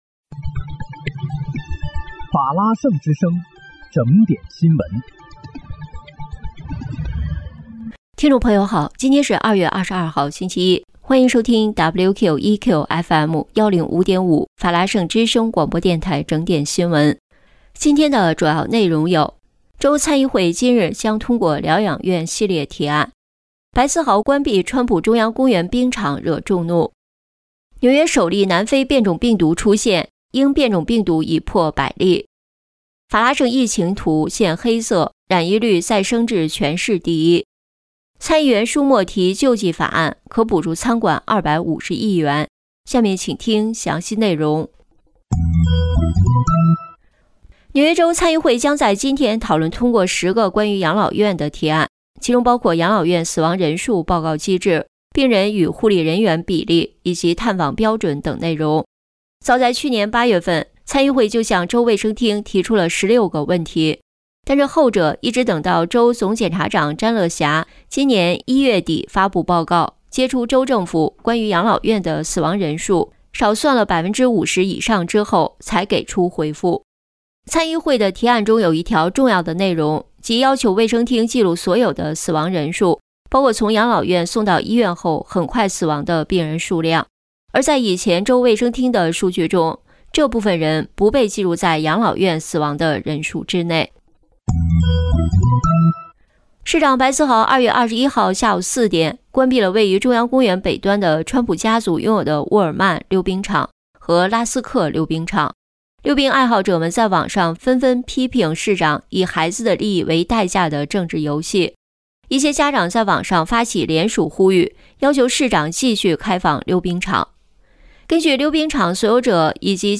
2月22日（星期一）纽约整点新闻